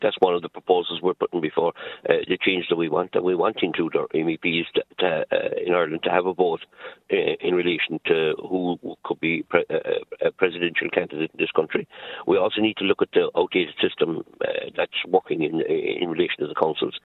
Leader of Independent Ireland Michael Collins says change is needed in the way candidates are nominated: